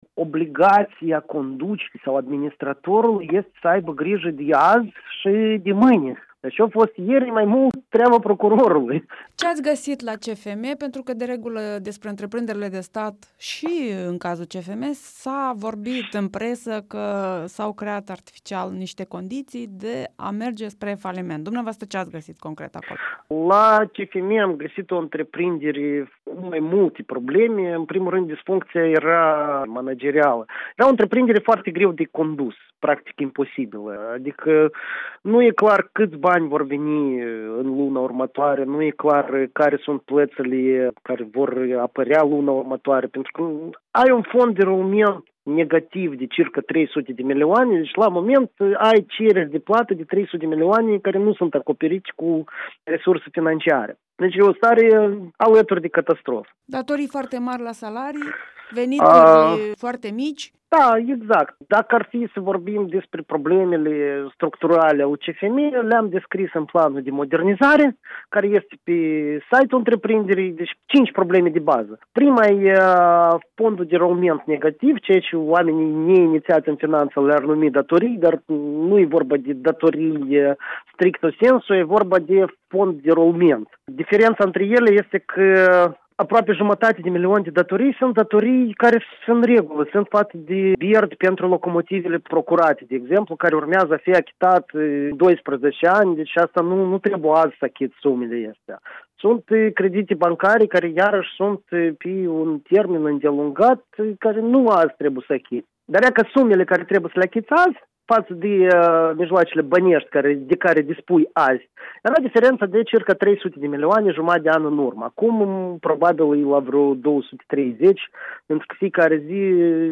Interviu cu direcgtorul general al Căilor Ferate ale Moldovei, Oleg Tofilat